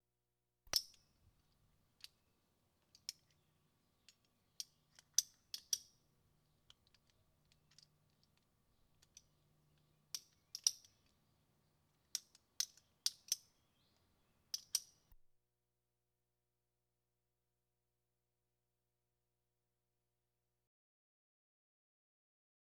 transport
Car Seat Belt Safety Harness Clip And Unclip 2